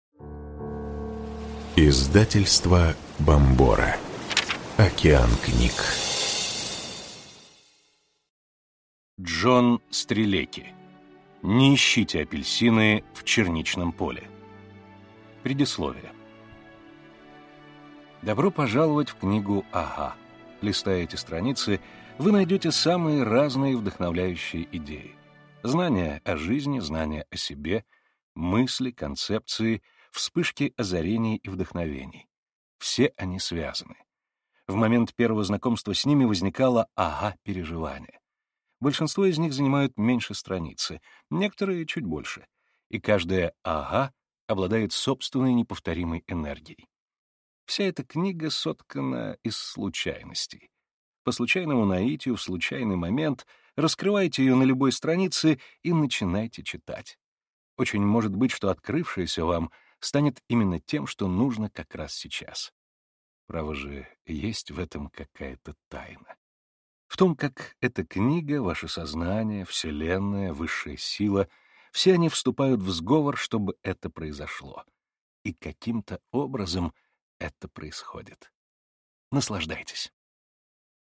Аудиокнига Не ищите апельсины в черничном поле | Библиотека аудиокниг